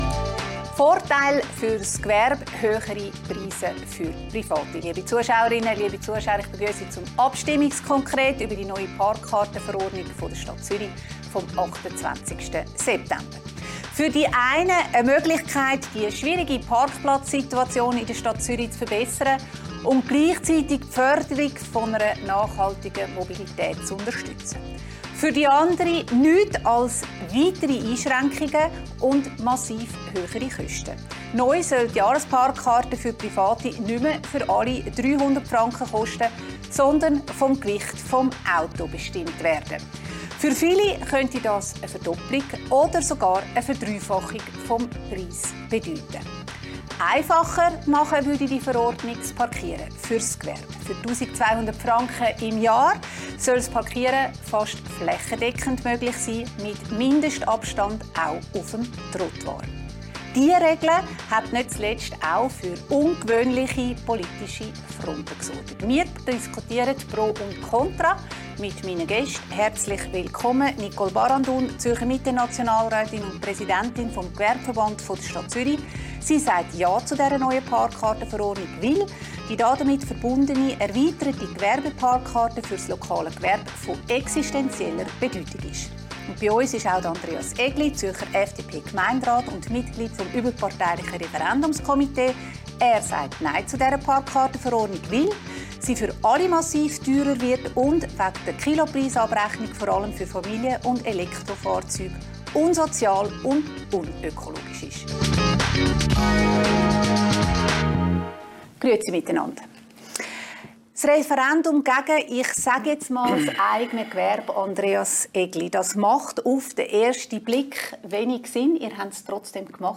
im Gespräch mit der Präsidentin des Gewerbeverbands der Stadt Zürich, Nationalrätin Nicole Barandun, Mitte, und FDP-Gemeinderat Andres Egli über die neue Parkkartenverordnung in der Stadt Zürich